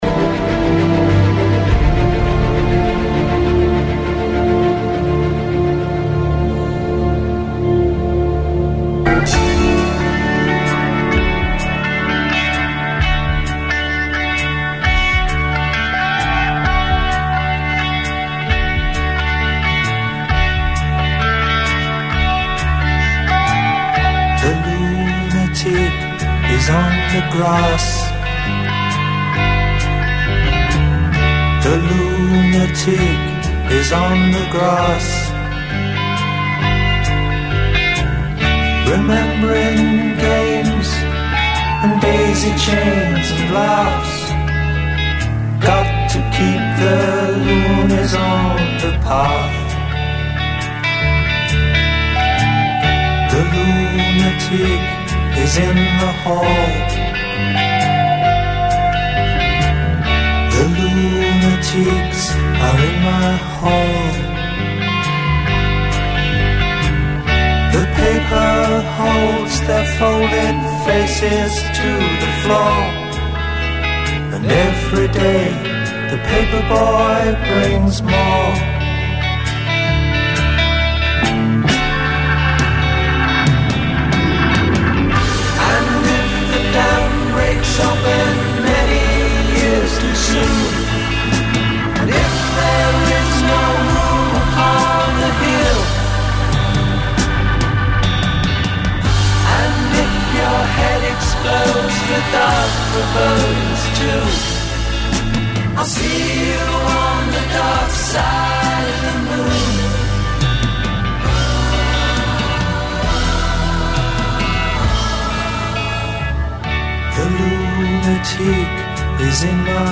Programa especial dedicado al Folk americano contemporáneo , con invitado sorpresa y alguna que otra joyita de Folk antiguo.
caramuel_27_folkcontemporaneo.mp3